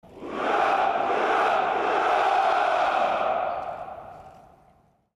Яркие и радостные возгласы толпы идеально подойдут для монтажа видео, создания праздничных роликов, поздравительных открыток или использования в проектах.
Солдаты в строю издают звук тройного крика ура